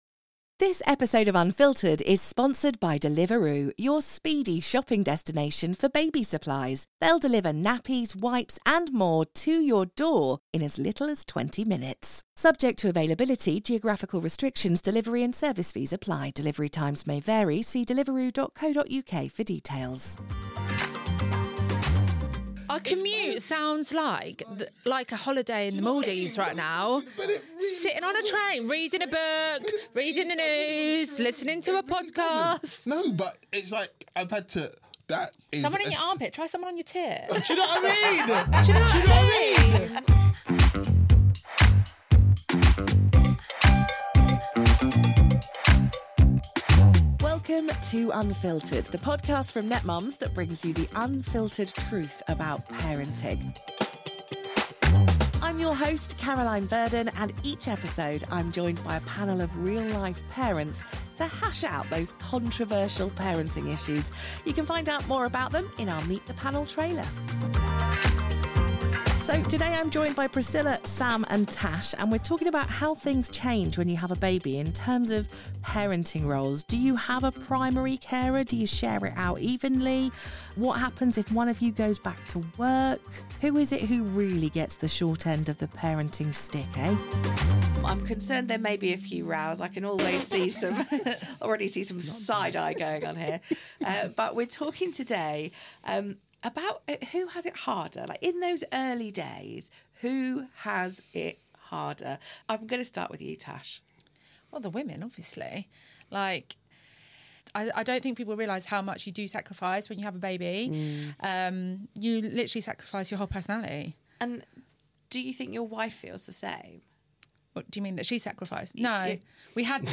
What are they talking about? - The impact of having a baby on parenting roles - Who really has it harder in the early days? - The balance between work and parenting - Managing mental health and self-care as a parent Join the lively discussion as our panel shares their personal experiences and debates the challenges of parenting roles, the sacrifices made, and the importance of mental health and self-care.